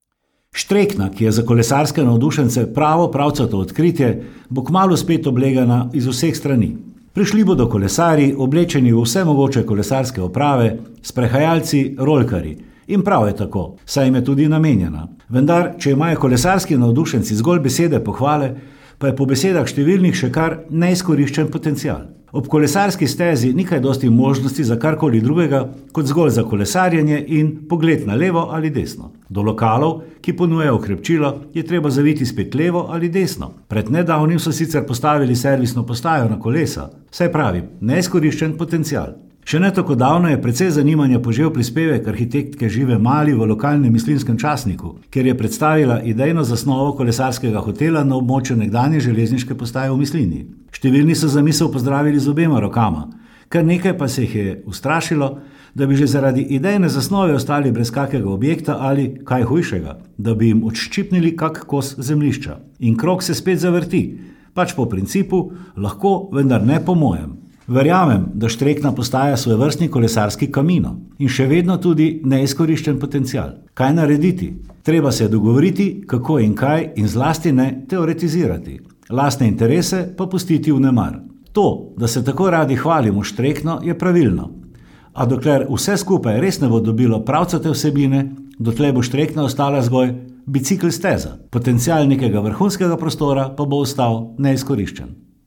Komentar je stališče avtorja in ni nujno stališče uredništva.